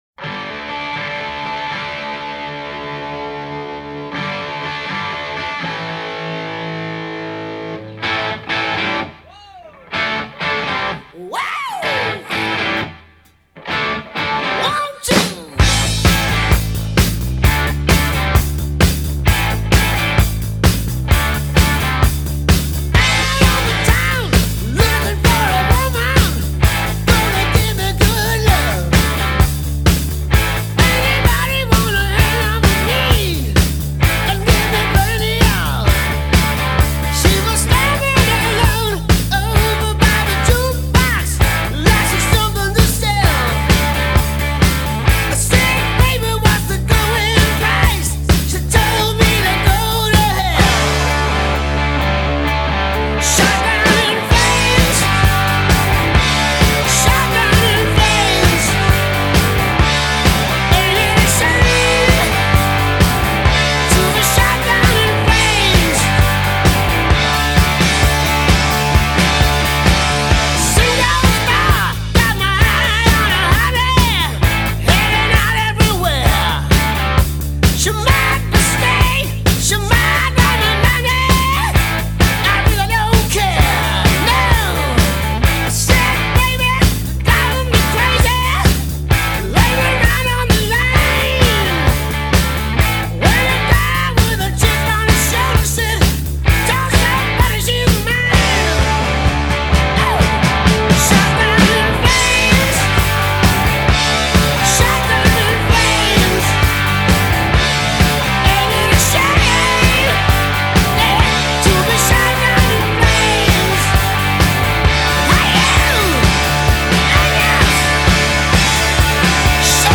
Volume a palla, stile e sregolatezza e tanto, tanto Rock.
Voce
Basso
Batteria
Chitarra Ritmica
Chitarra Solista